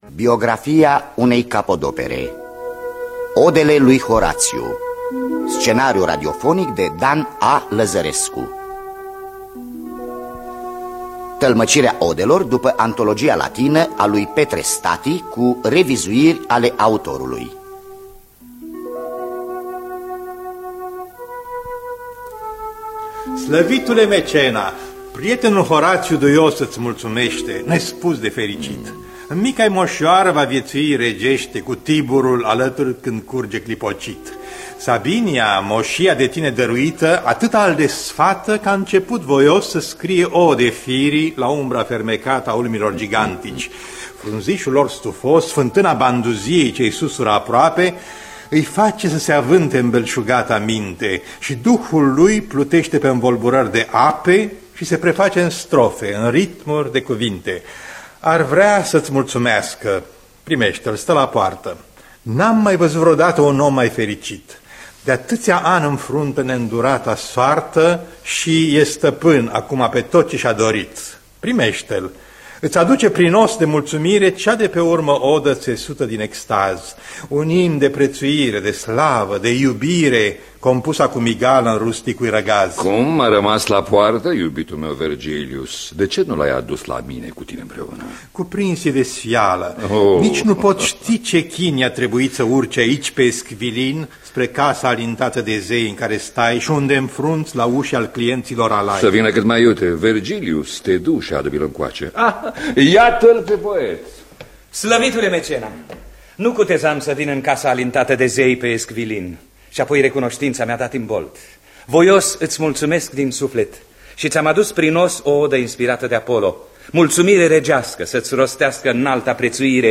Biografii, memorii: Odele lui Horațiu. Scenariu radiofonic de Dan Amedeo Lăzărescu.